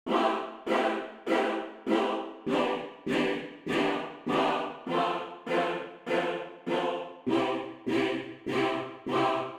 例えば「Square」パターンを選択し、オービット速度を好みに合わせて設定すると、下記のようなサウンドが作成できます。
▼4つの母音をブレンドしたサウンド